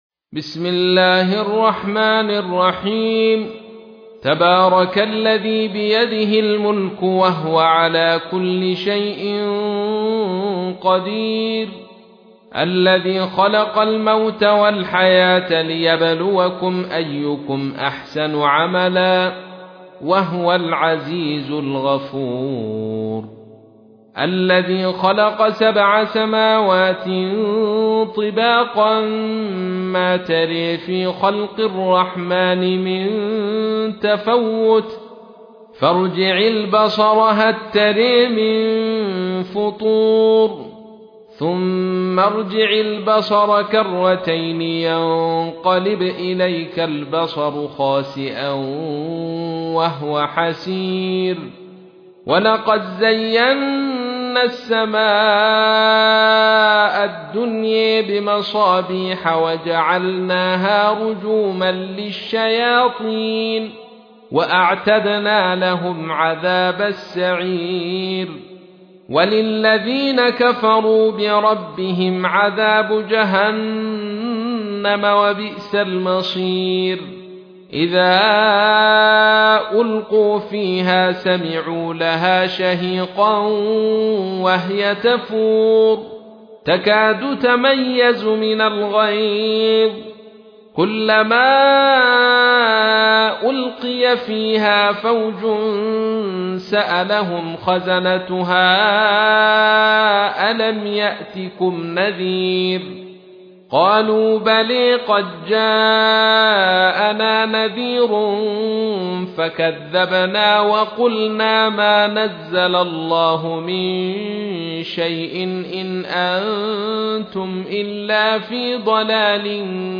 تحميل : 67. سورة الملك / القارئ عبد الرشيد صوفي / القرآن الكريم / موقع يا حسين